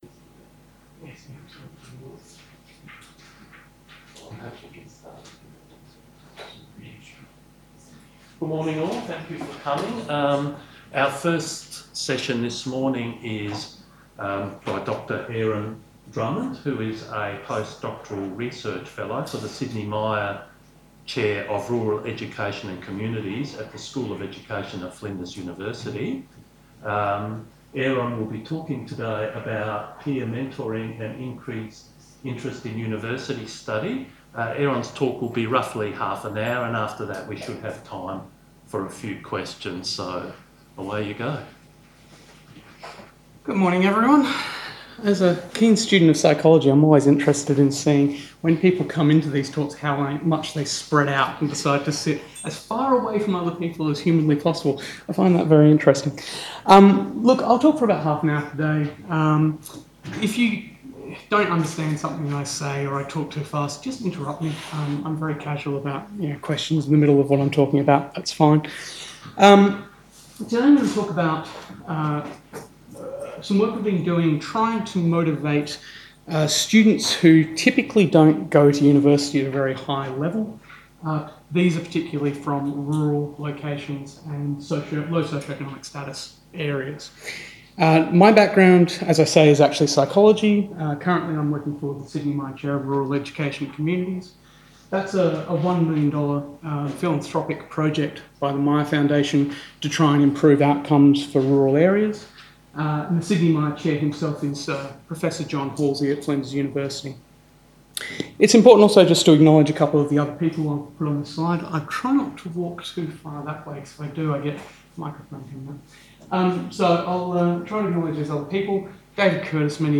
Findings from this research were showcased at the one-day national forum where leading researchers from across the tertiary education and training sector discussed aspirations and student participation, diversity and equity issues and labour market outcomes. They also shared their insights into the barriers to educational participation, completion and outcomes for disadvantaged learners.